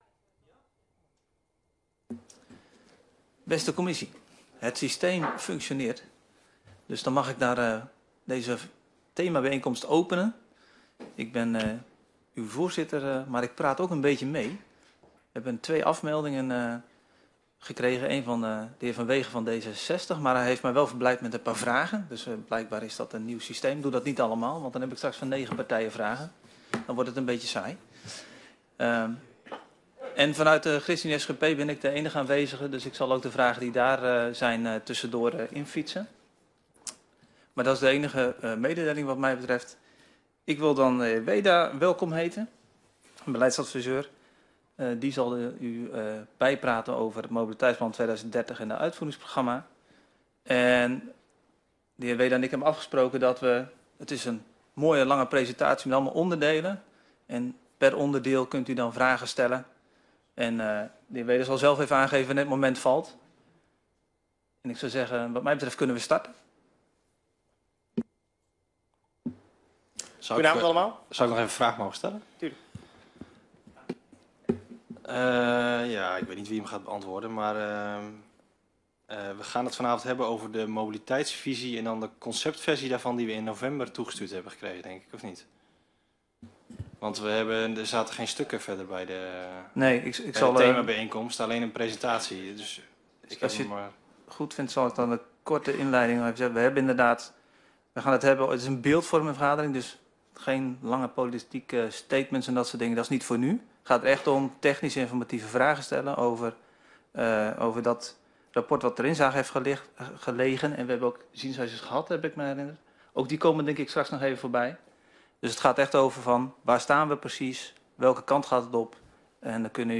Themabijeenkomst 09 februari 2023 19:30:00, Gemeente Noordoostpolder
Locatie: Raadzaal